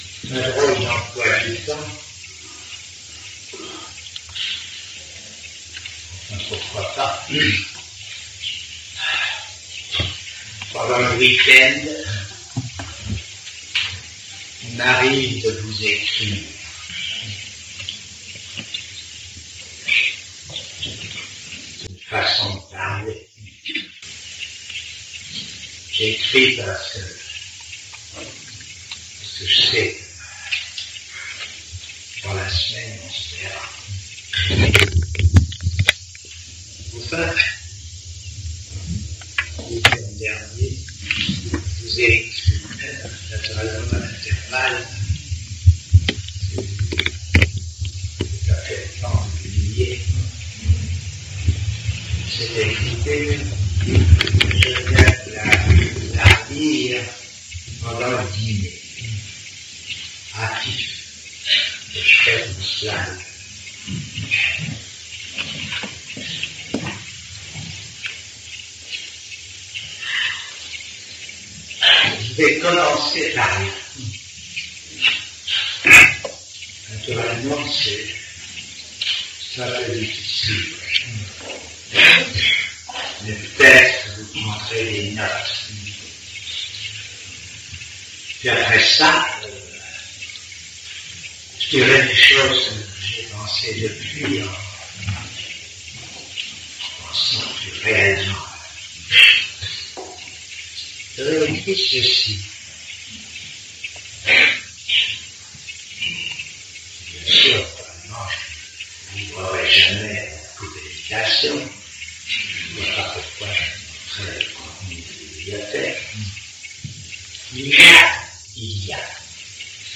Audios mp3 améliorée (dans la mesure du possible)